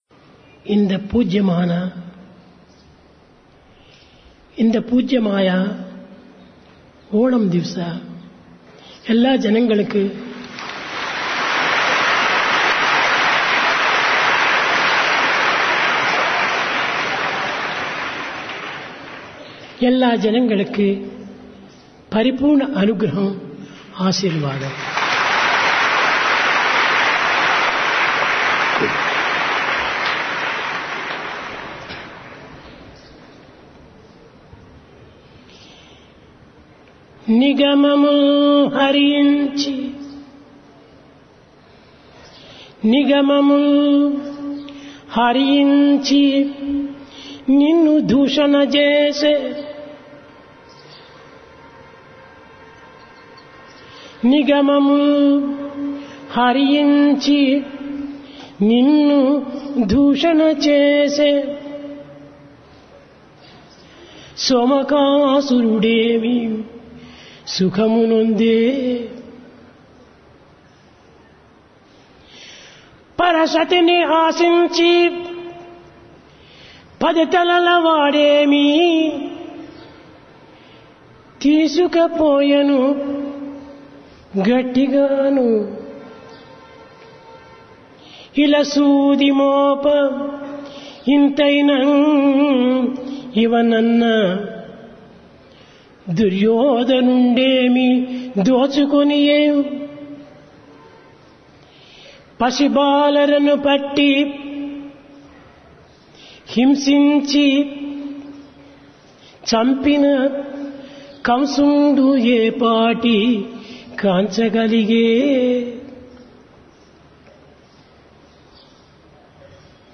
Divine Discourse of Bhagawan Sri Sathya Sai Baba, Sri Sathya Sai Speaks, Vol 34 (2001) Date: 31 August 2001 Occasion: Onam
Place Prasanthi Nilayam Occasion Onam